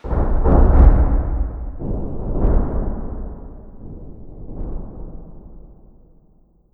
truenitos_2.wav